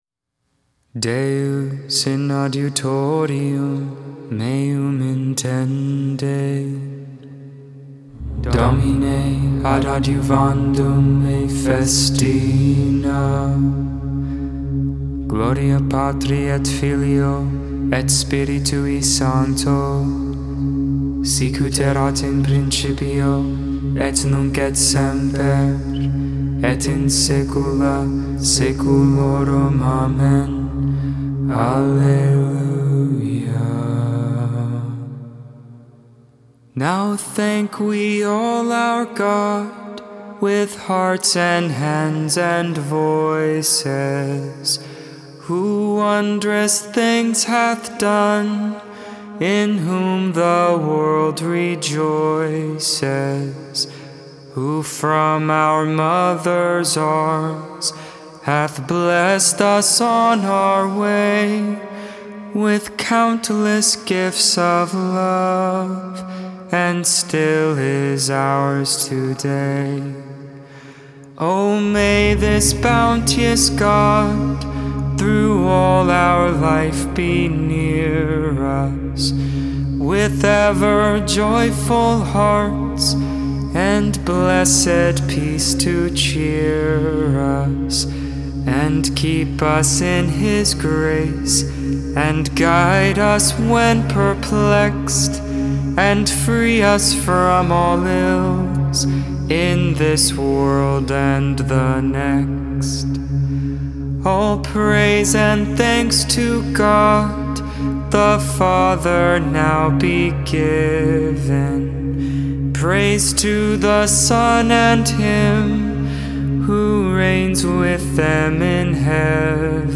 7.24.21 Vespers I, Saturday Evening Prayer